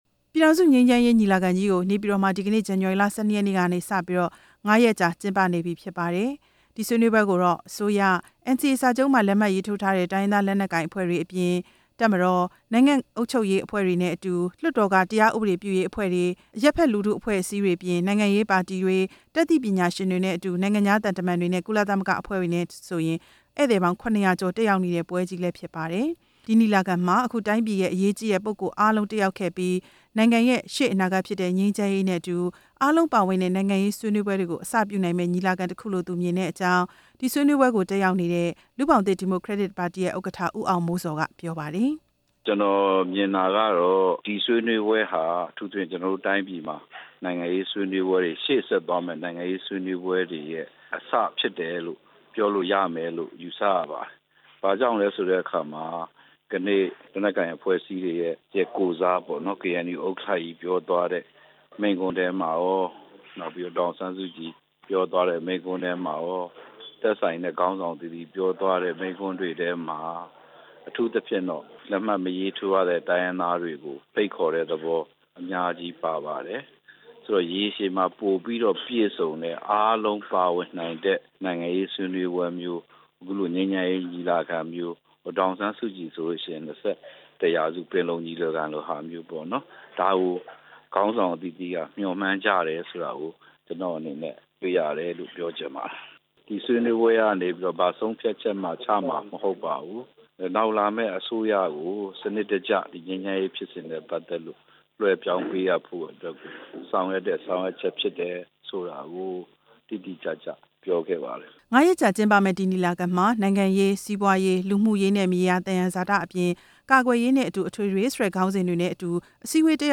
နေပြည်တော်မှာ ဒီကနေ့ စတင်ကျင်းပနေတဲ့ ပြည်ထောင်စုငြိမ်းချမ်းရေးညီလာခံကို တက်ရောက်နေသူတချိုရဲ့ အမြင်နဲ့ သုံးသပ်ချက်တွေကို